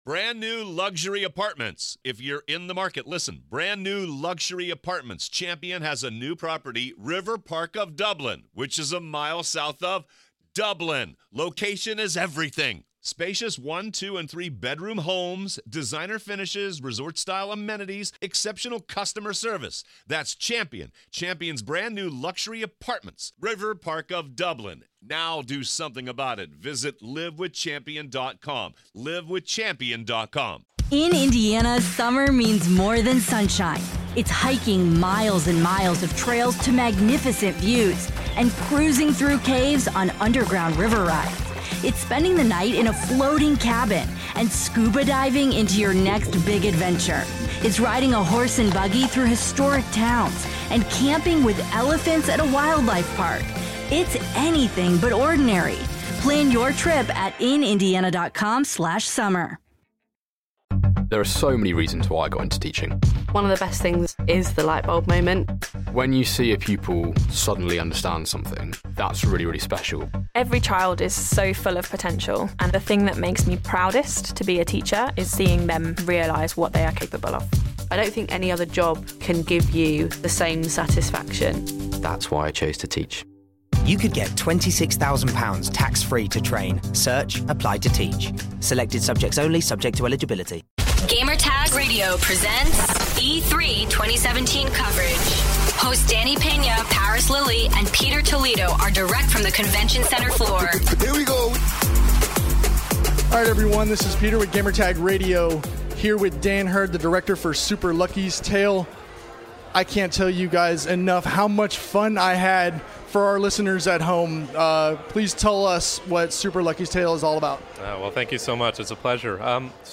E3 2017: Super Lucky's Tale Interview